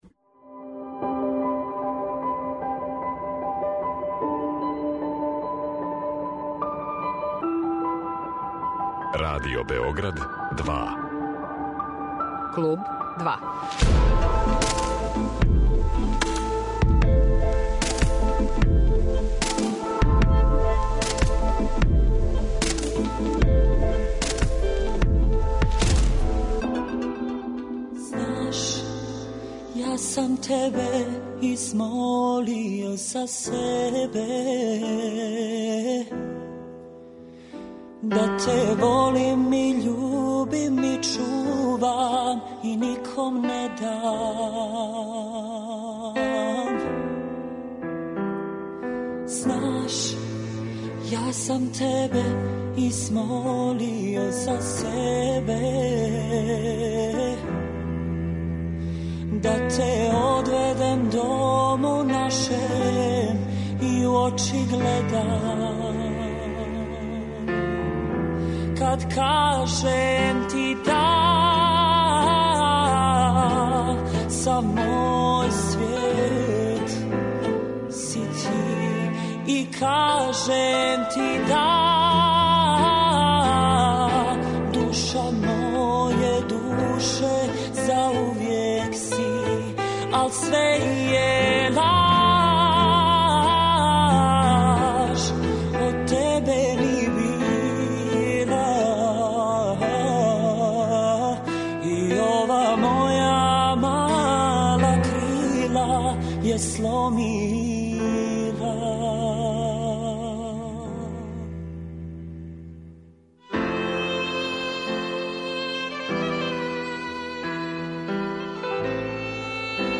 Божо Врећо, гост емисије
Гост емисије Клуб 2 биће Божо Врећо, уметник/ца.